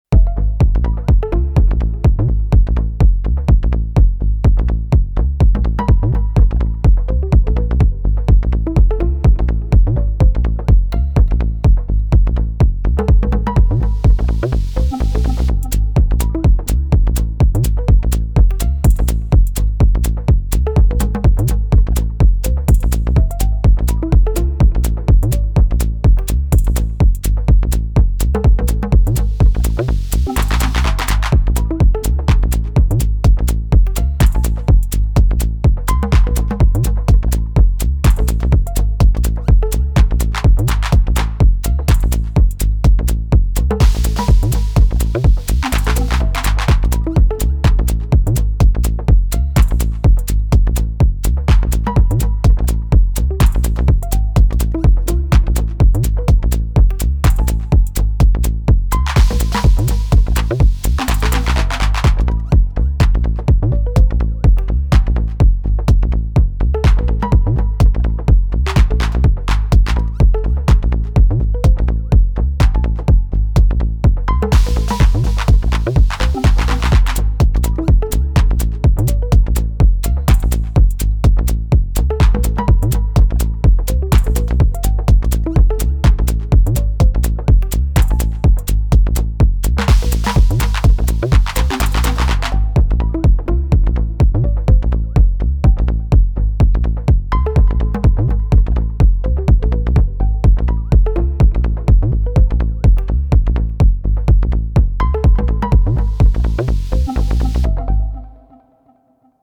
My best late 00s early 10s Minus Techno impression with the Syntakt and a bit of compression.